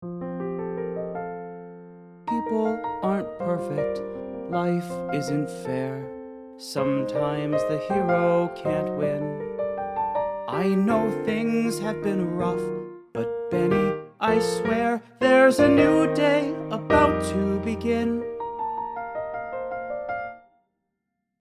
Since we’re in 6/8, the strongest beats are “1” and “4” if we’re counting “1, 2, 3, 4, 5, 6″ or beats “1” and “2” if we’re counting it as a compound meter of 2 beats, as in, “1 & a, 2 & a.”